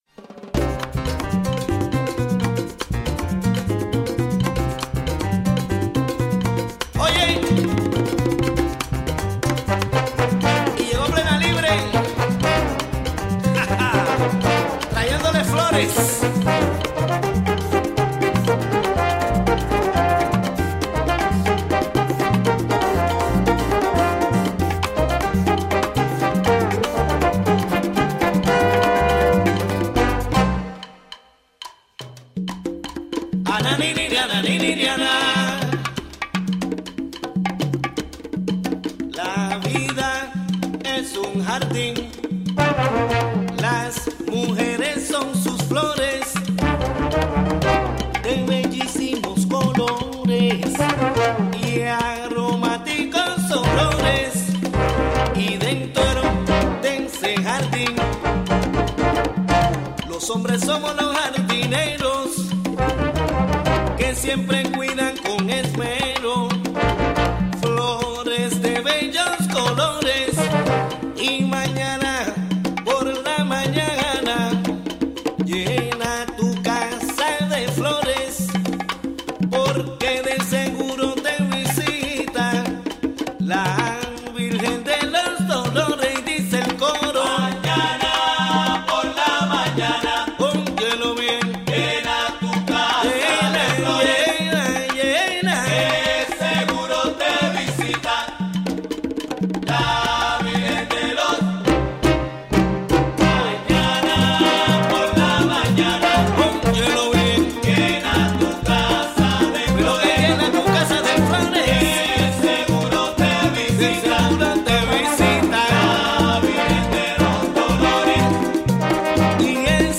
Conversations with Peruvian artist